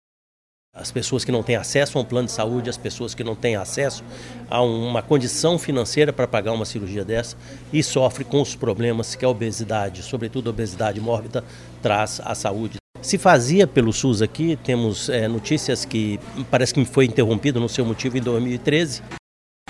Como destaca o secretário de Saúde do Amazonas, Anoar Samad.
Sonora-Anoar-Samad-secretario-de-saude-do-Amazonas.mp3